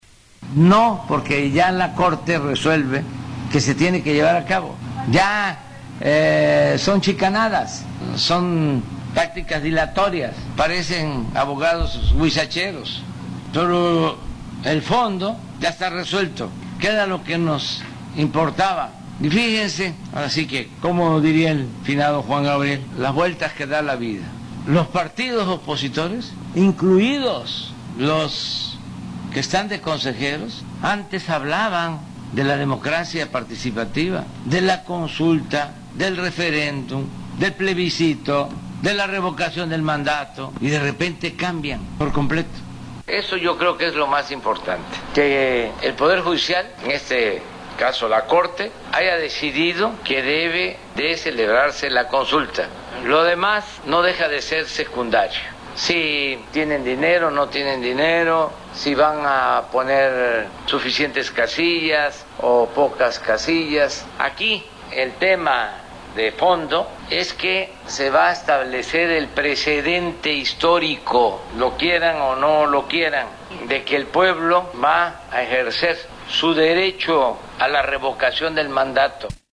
“Son chicanadas, son tácticas dilatorias, parecen abogados huizacheros, el fondo ya está resuelto que era lo que nos importaba”, sostuvo en conferencia de prensa desde Tabasco.